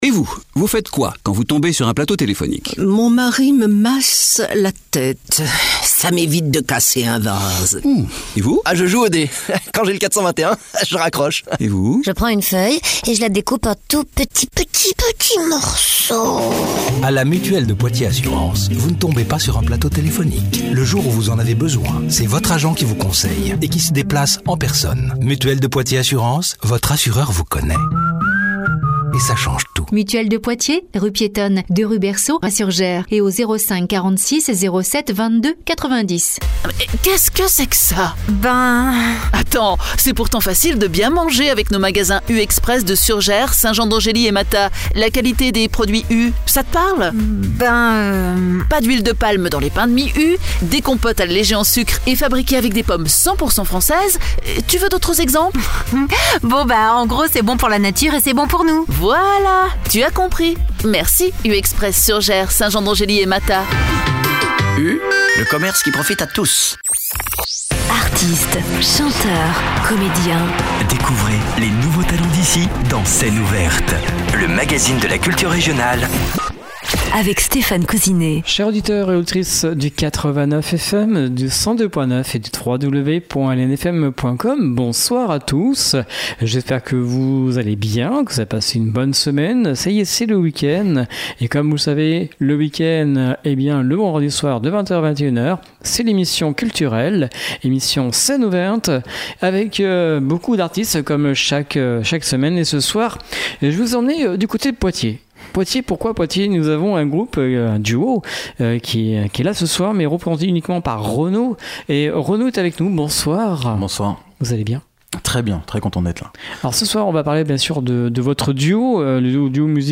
Alto
Violoncelle) ; un cocktail de chanson, de rock et de hip hop
Encore plus rock, résolument plus hip hop et plus sombre